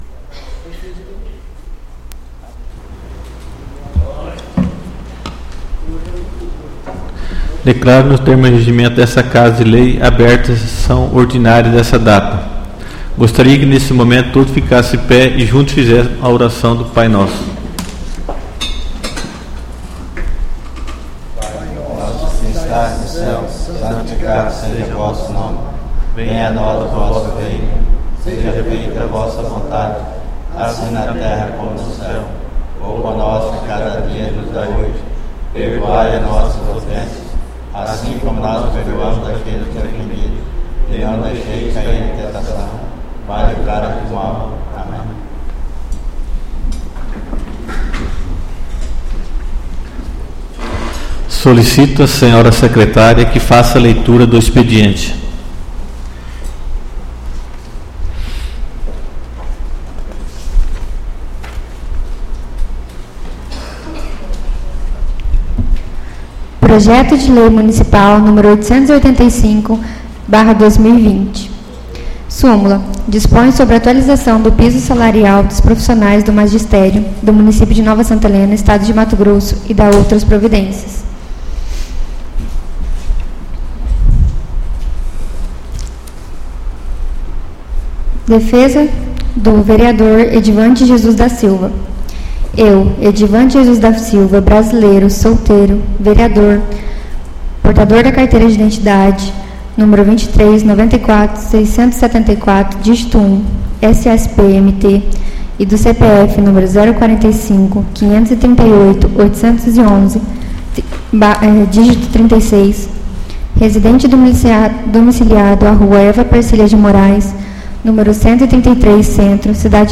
SESSÃO ORDINÁRIA DO DIA 11/02/2020